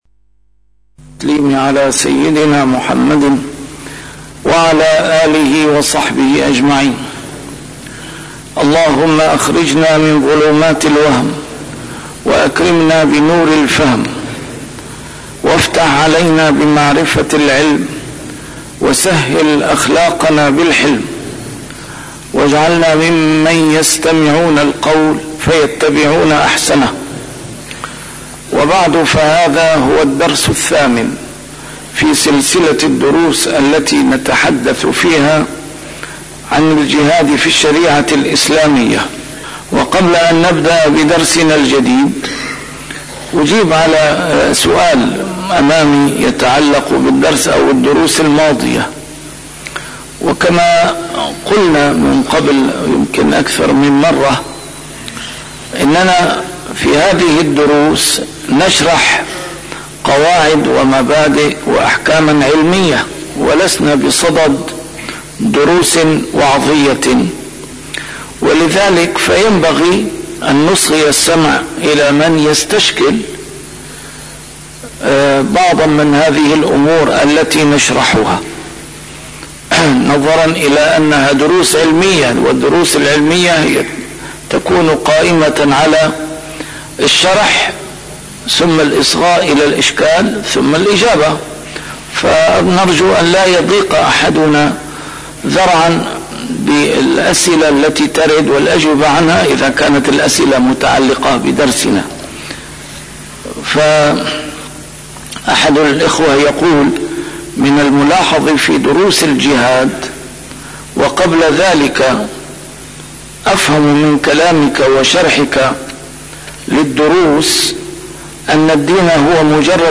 نسيم الشام › A MARTYR SCHOLAR: IMAM MUHAMMAD SAEED RAMADAN AL-BOUTI - الدروس العلمية - الجهاد في الإسلام - تسجيل قديم - الدرس الثامن: دار الإسلام والمجتمع الإسلامي